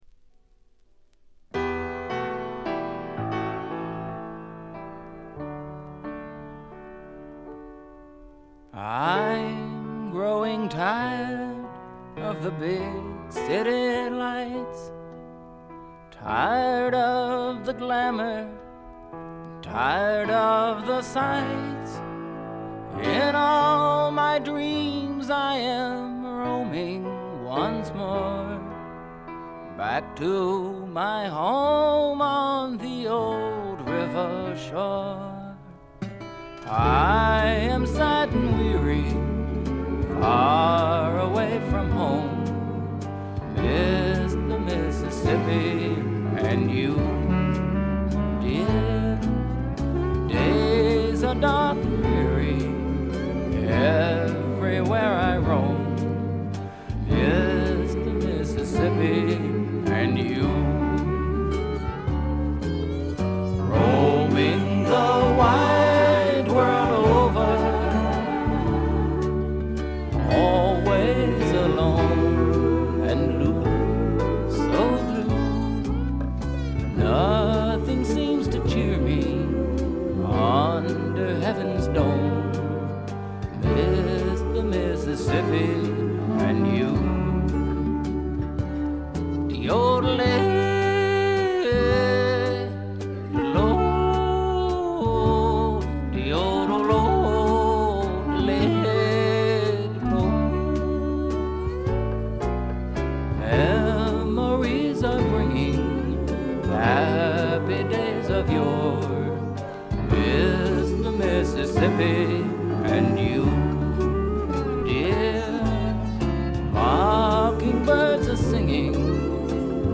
これ以外はほとんどノイズ感無し。
試聴曲は現品からの取り込み音源です。